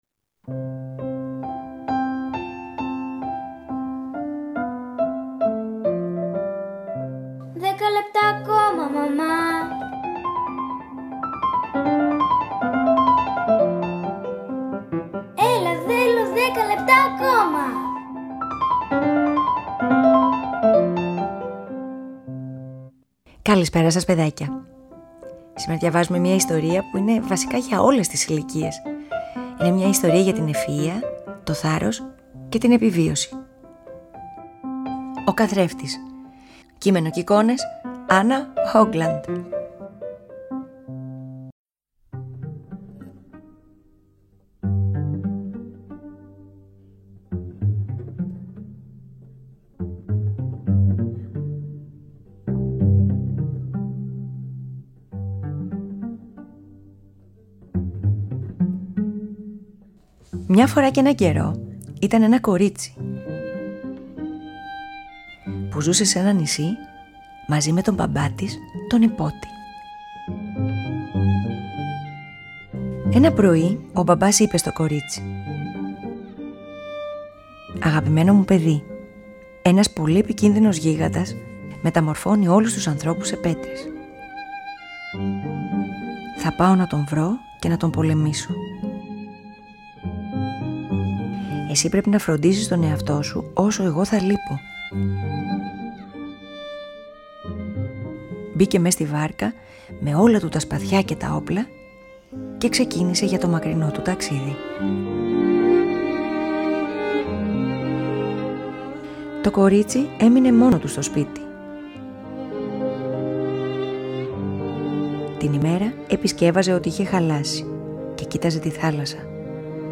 Αφήγηση-Μουσικές επιλογές
ΠΑΡΑΜΥΘΙΑ